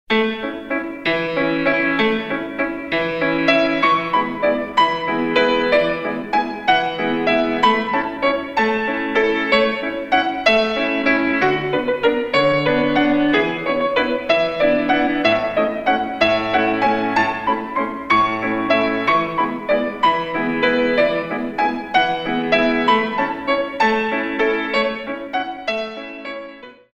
64 Counts